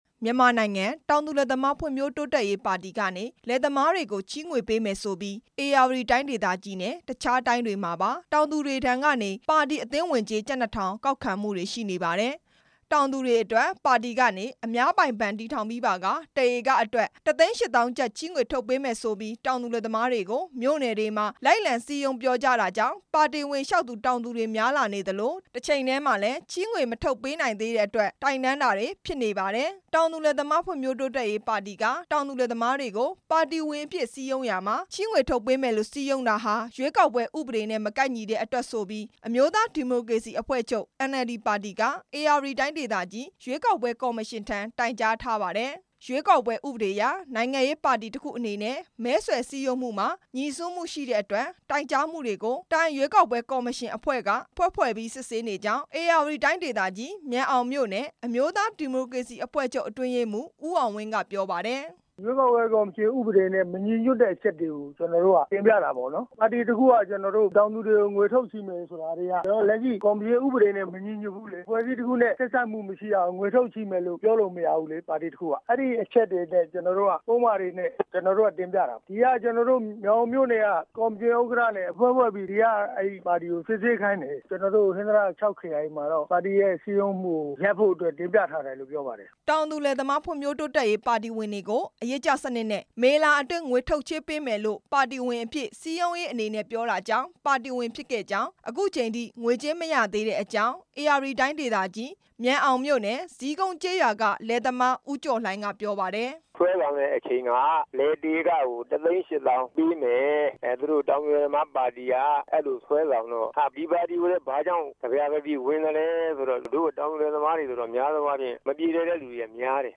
RFA သတင်းထောက်